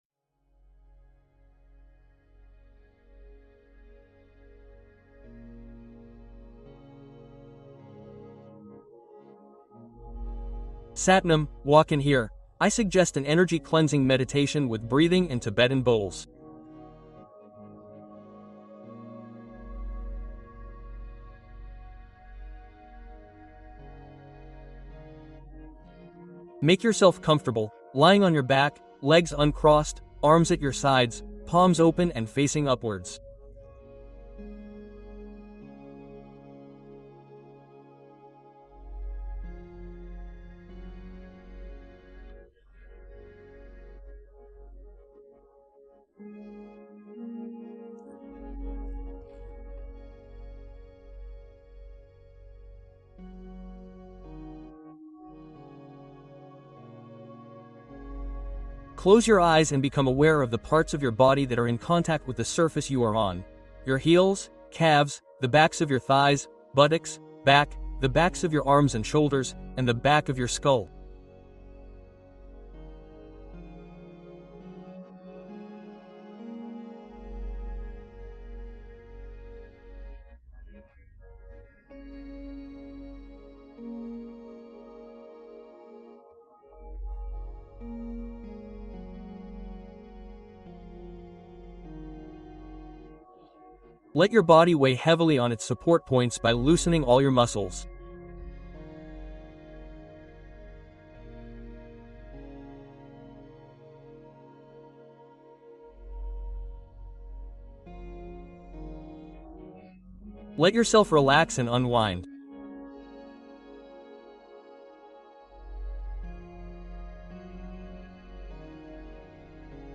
Bols Tibétains : Le nettoyage énergétique qui dissout anxiété, peurs et stress instantanément